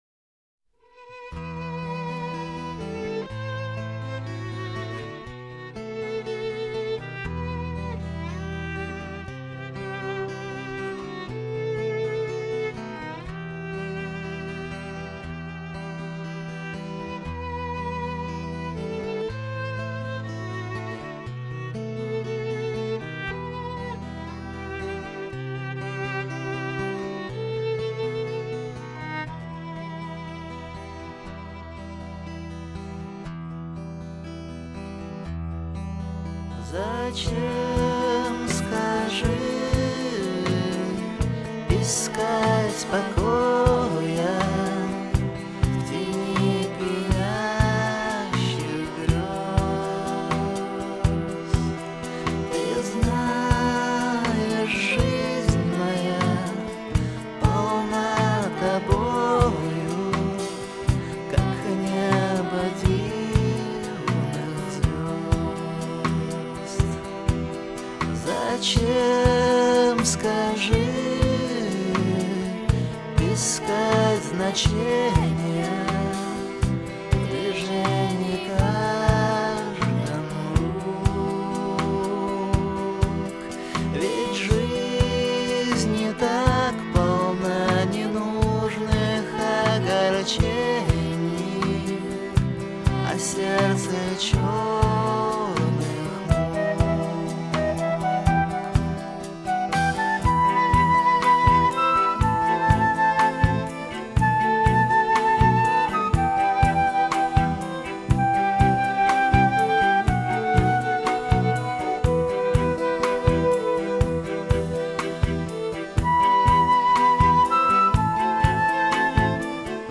фиоле... сире... всё-равно - блюз! )